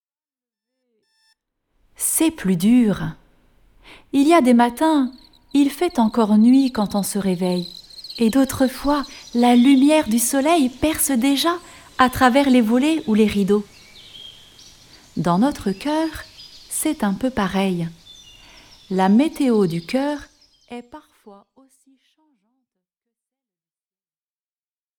Mini-catéchèse